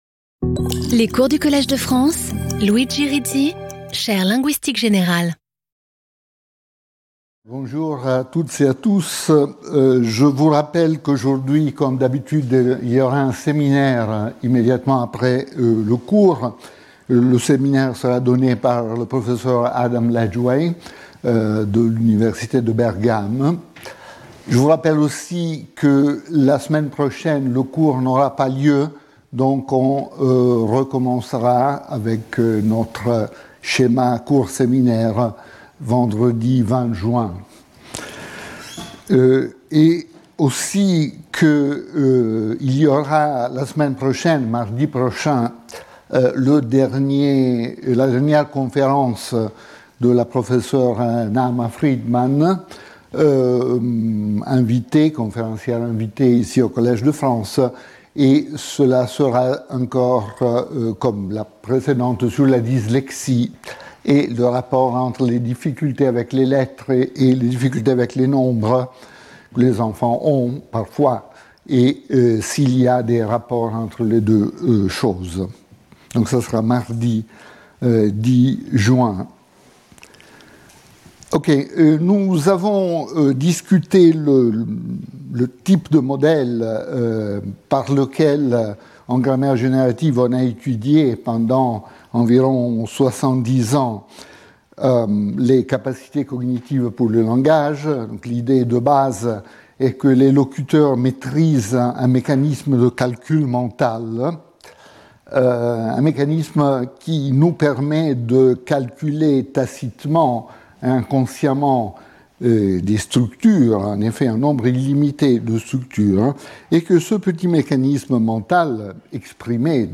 The study of the nature and properties of these principles is one of the important themes of formal language research. Speaker(s) Luigi Rizzi Professor at the Collège de France
Lecture